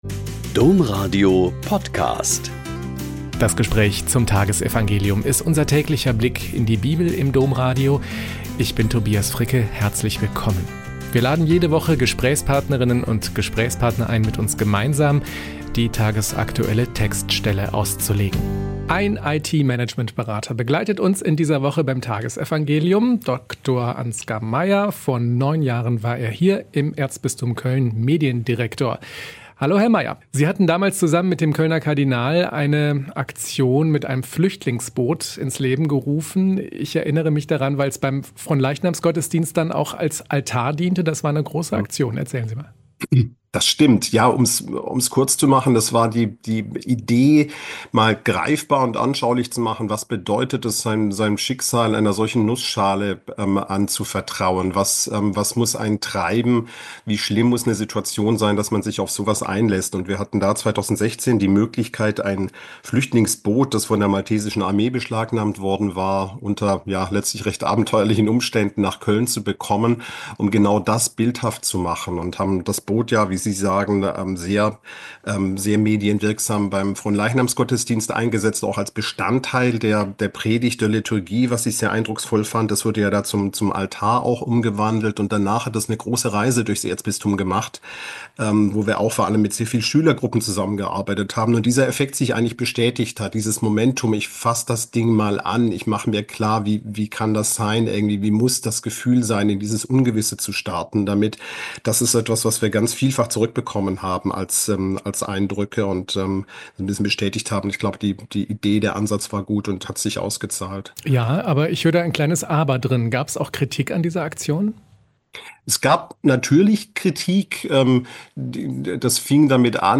Mk 6,34-44 - Gespräch